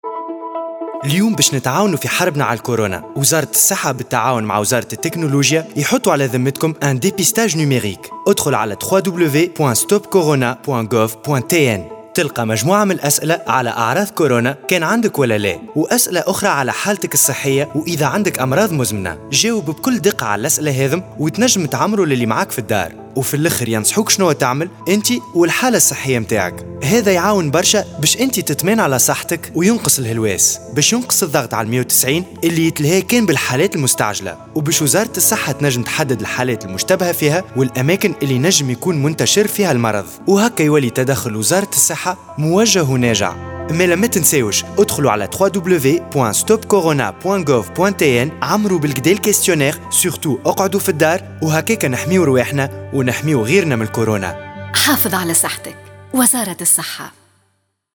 Spot radio dépistage numérique COVID-19
MS-COVID19-Depistage-en-ligne-Spot-Radio-1.mp3